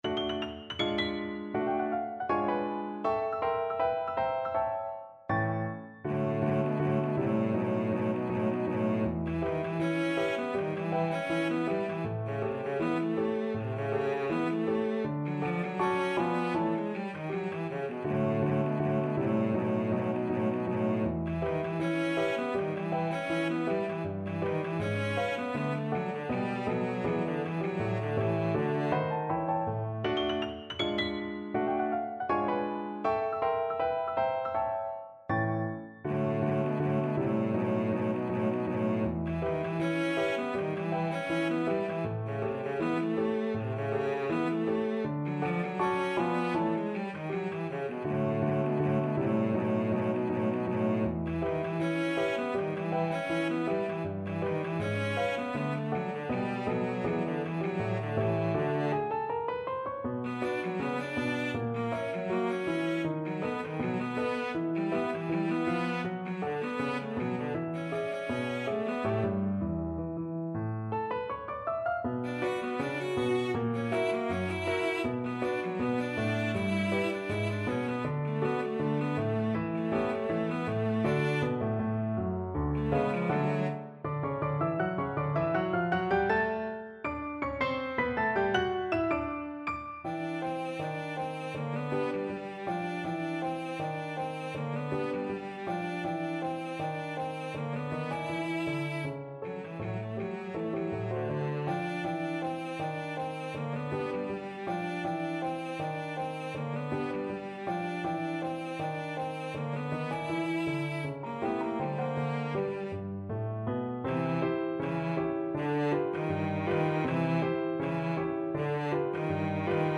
Cello version
2/2 (View more 2/2 Music)
Jazz (View more Jazz Cello Music)